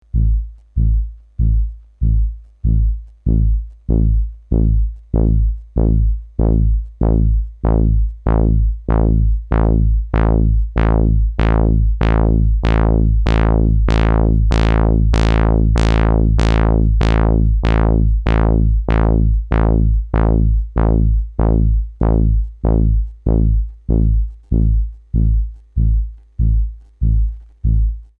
Example sounds, no effects used, input signal is static saw wave from function generator:
filter sweep ramp down, low Q
ldr-filtersweep-rampdown-saw55hz-lowq.mp3